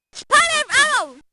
In the final, she says "spiral arrow!".